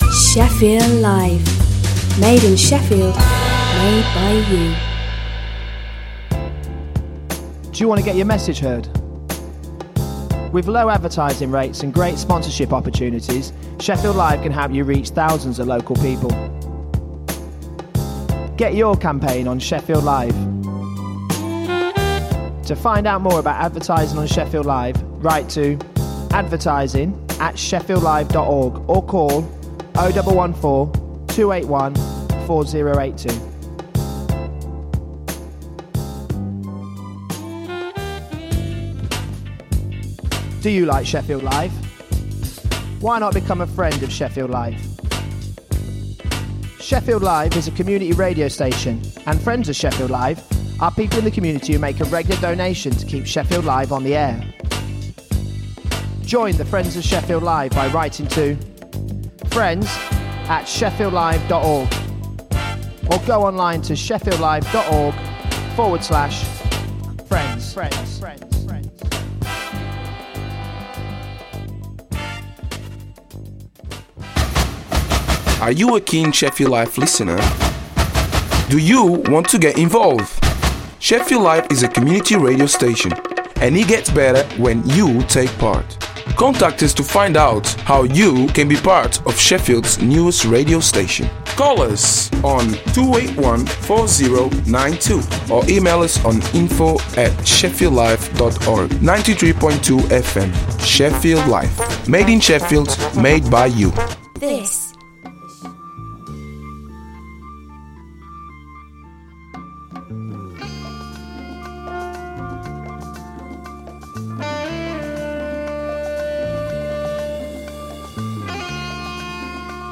A programme that introduces all different styles of Latin music from its roots and at the same time inform the audience of the latest issues in Latin America, also interviews with artists visiting the city (not necessarily South American).